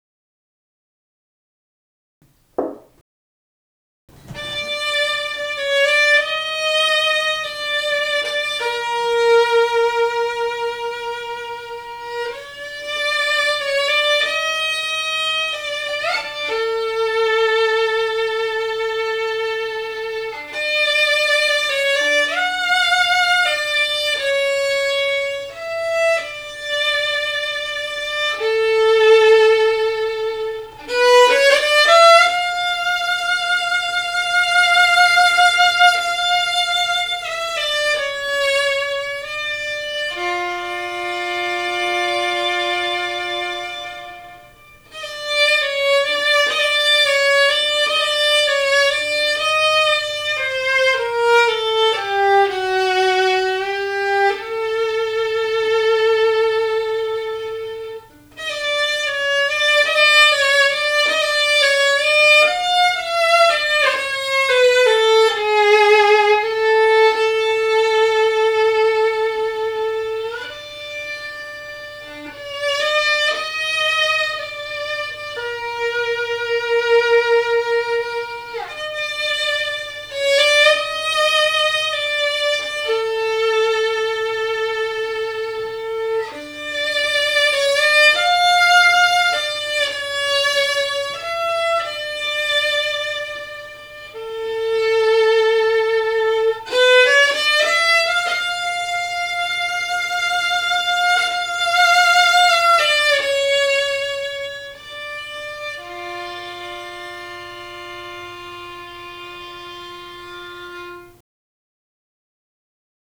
The acoustics here in our living room are exceptional, and really help to 'hear' accurately.
Click the four violins below: to hear real samples of "Rich" Tuned violins...